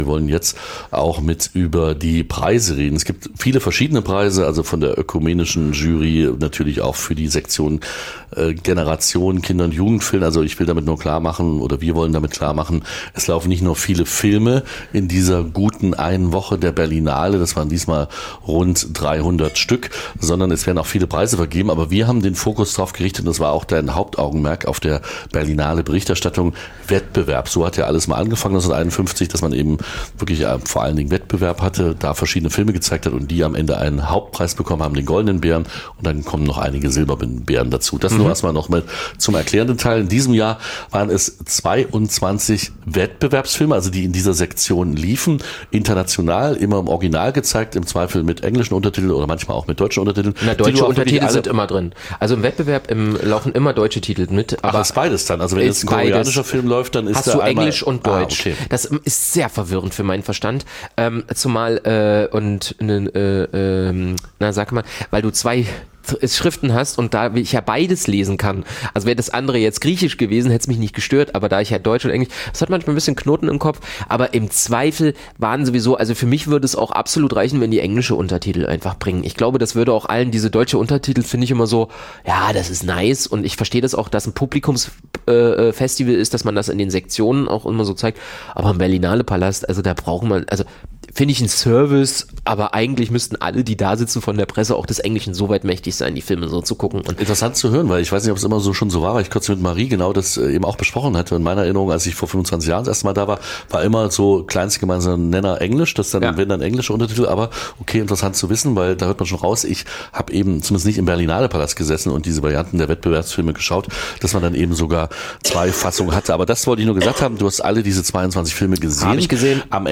Berlinale-Abschluss: ein letzter Bericht